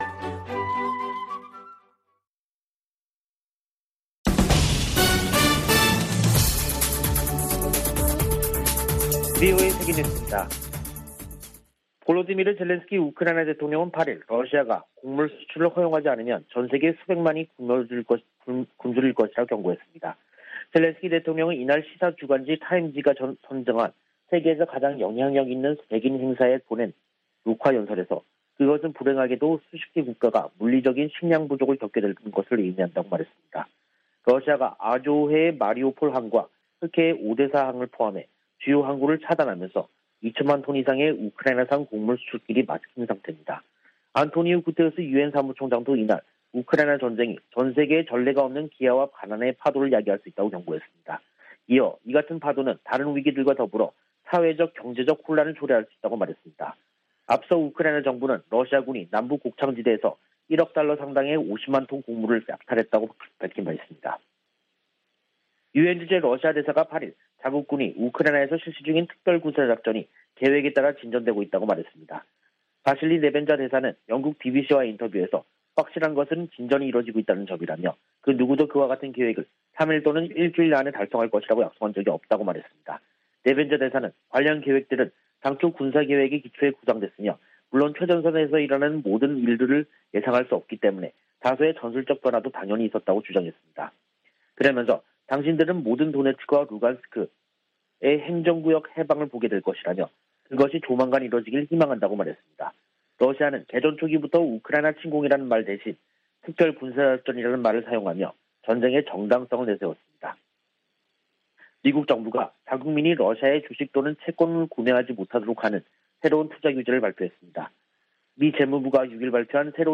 VOA 한국어 간판 뉴스 프로그램 '뉴스 투데이', 2022년 6월 9일 2부 방송입니다. 북한이 7차 핵실험을 강행하면 강력히 대응할 것이라는 방침을 백악관 고위당국자가 재확인했습니다. 미국의 B-1B 전략폭격기가 괌에 전진 배치됐습니다. 한국 외교부 김건 한반도평화교섭본부장과 중국의 류샤오밍 한반도사무특별대표가 전화로 최근 한반도 정세와 북한의 미사일 도발 대응 등에 의견을 교환했습니다.